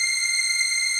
DM PAD3-02.wav